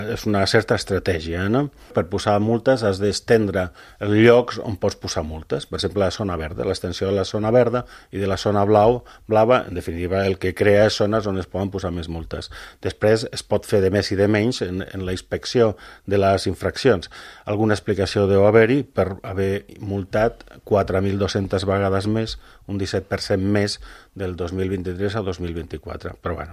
A l’entrevista política de RCT, Tejada ha insinuat que respon a una estratègia amb finalitats recaptatòries.